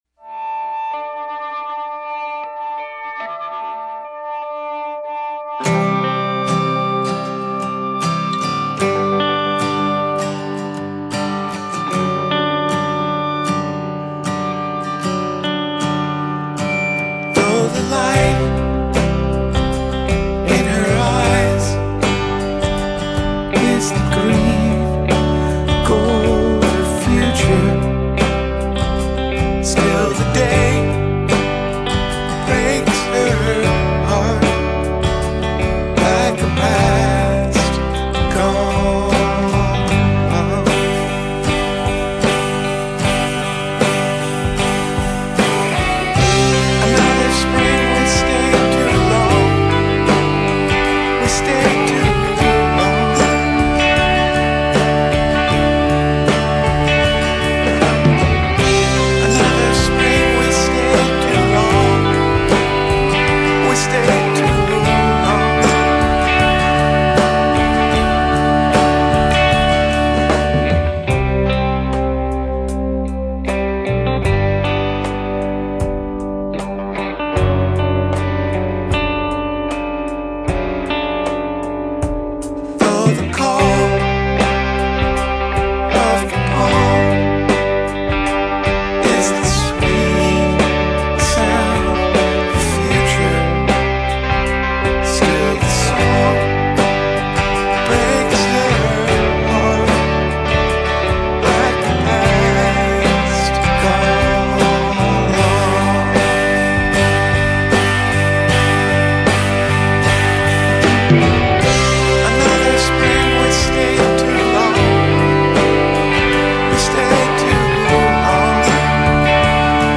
an indie rock band with members spread across three states
guitarist, vocalist
The vocal was added late, and the double after that.
drums
bass